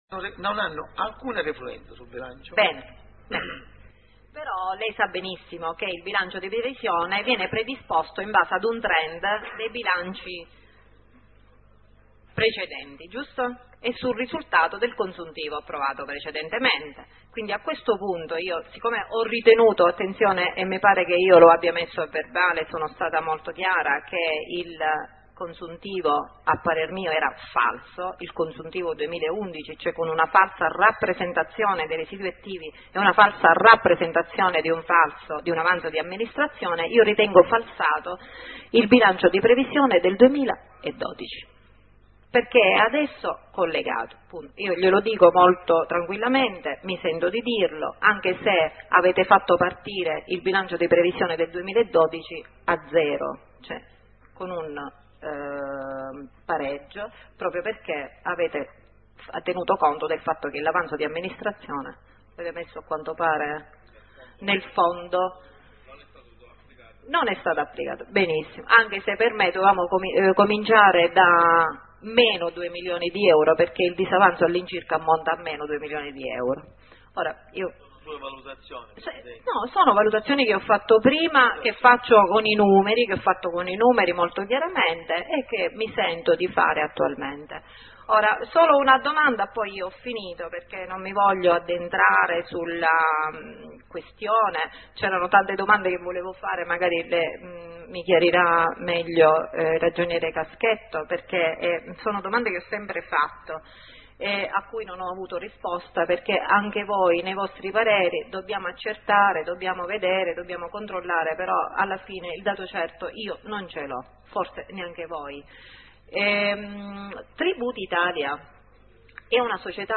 19 consiglio comunale 28.12.2012 .mp3 quarta parte